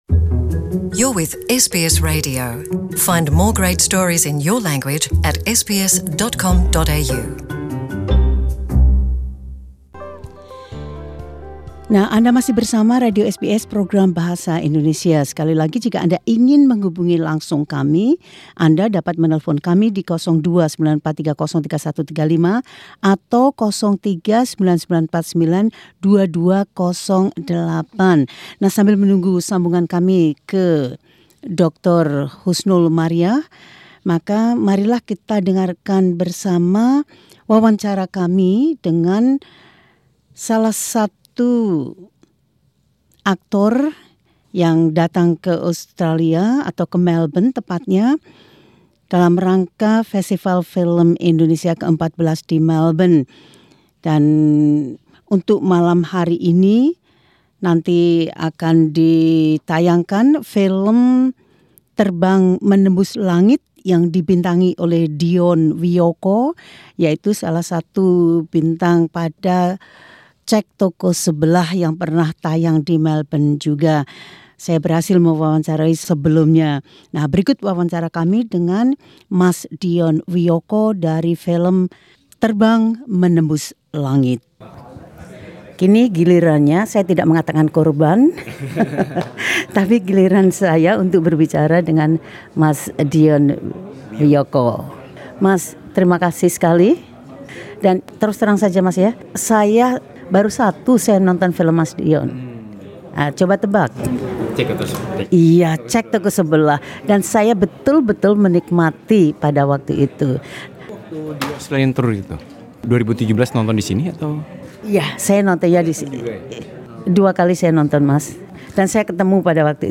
Aktor Dion Wiyoko berbicara tentang filosofi yang mendasari film “Terbang Menembus Langit” yang ditayangkan di Festival Film Indonesia ke-14, pada tanggal 8 April.